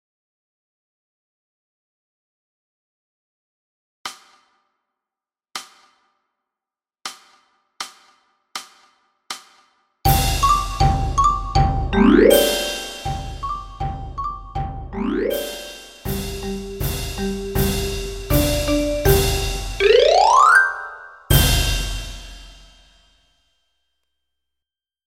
80 bpm